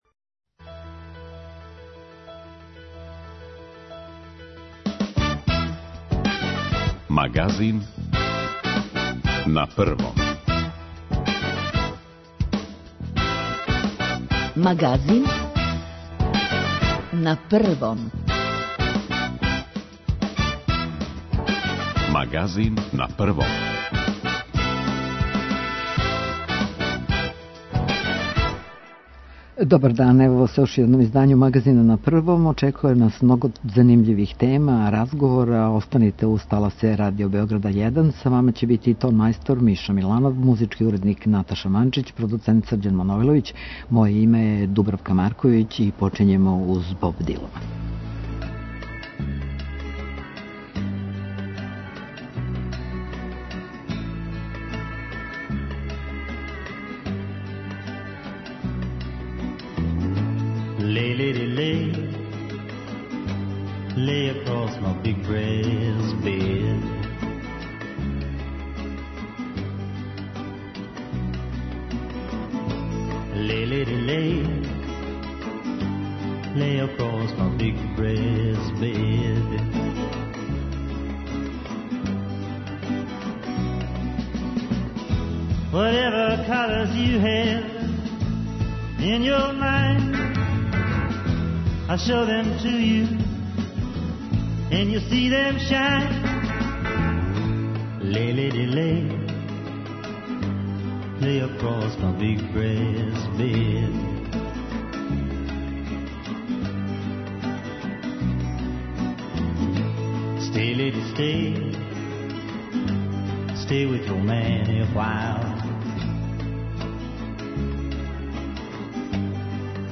Укључења репортера са догађаја и дописници из Србије биће и данас део нашег Магазина.